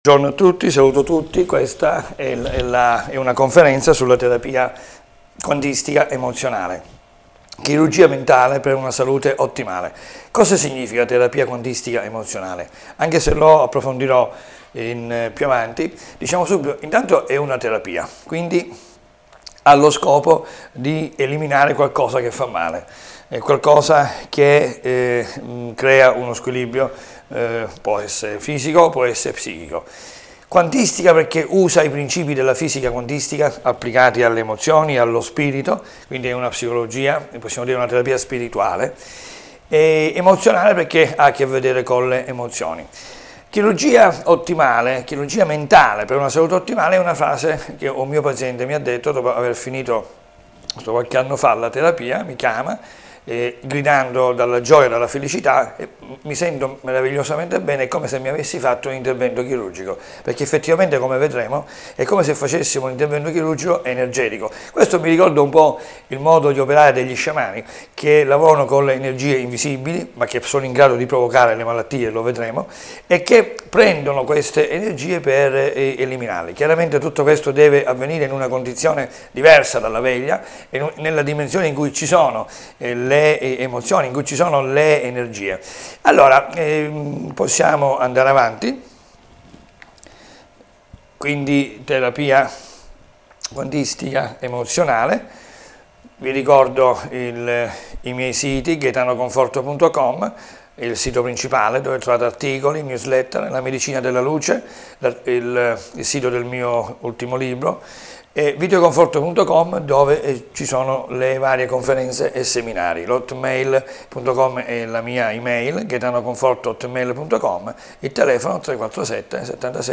Ascolta la conferenza introduttiva, le testimonianze, e altro....